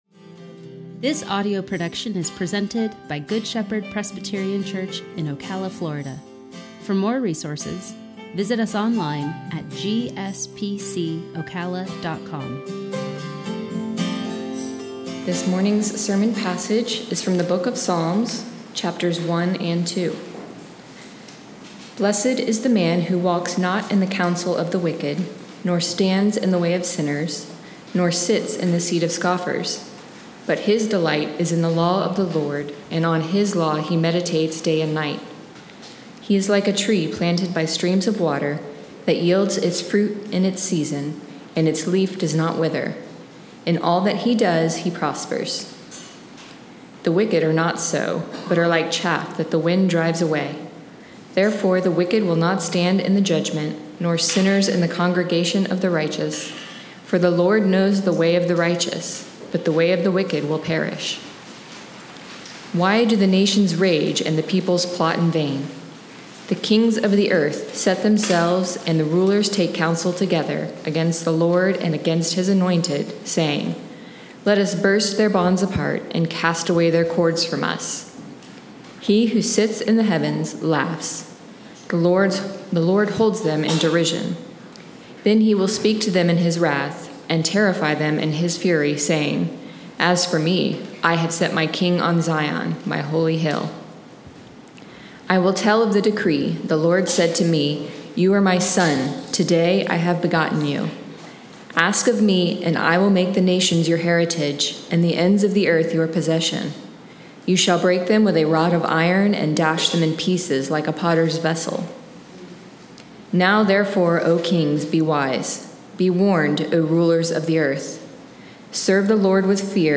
Sermon-7-25-21.mp3